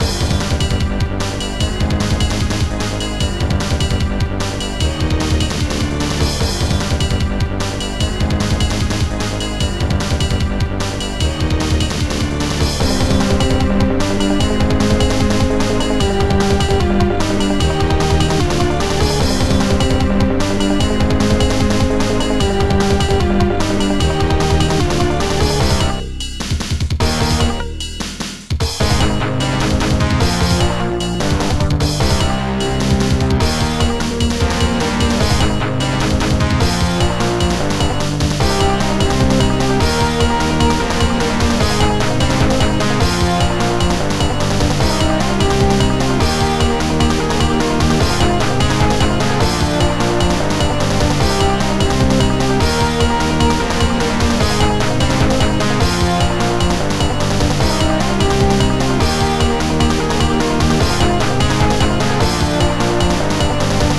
Impulse Tracker Module